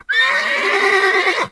c_whorse_hit1.wav